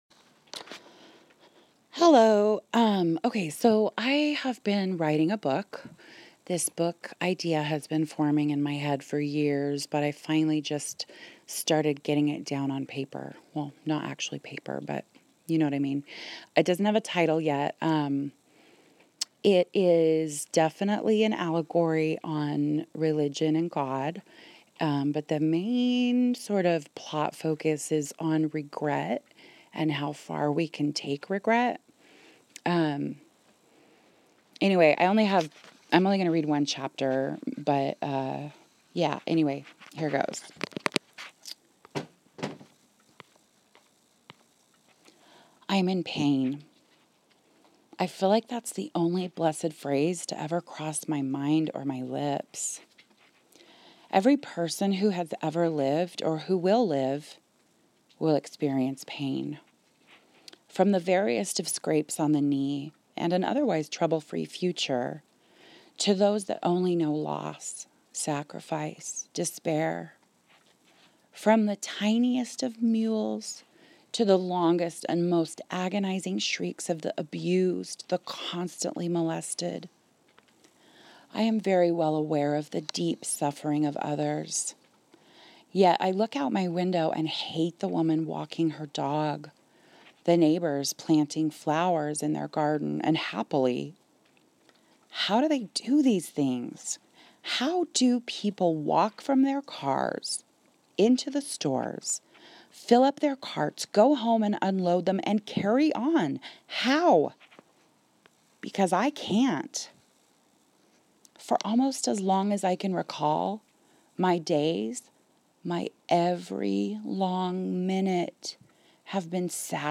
A Reading of Regret